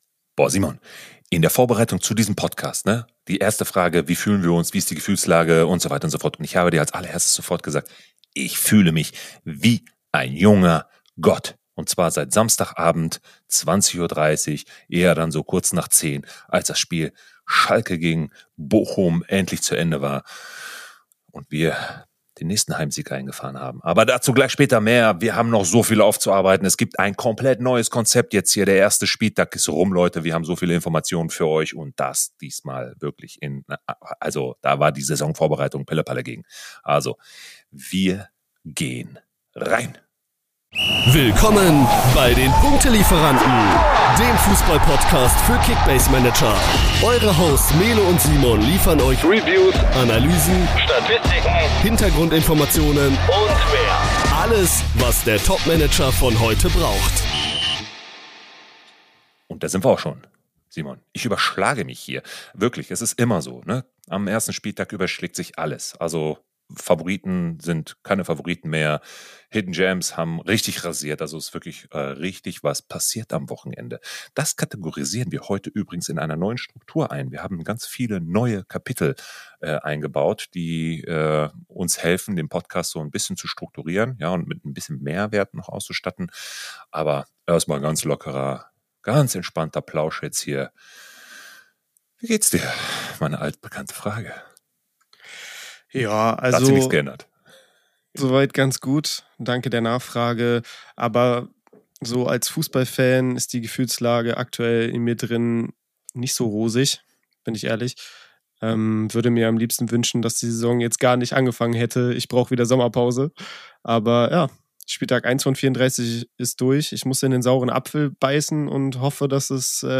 Unsere Hosts: Erlebe die perfekte Mischung aus Humor und Analyse!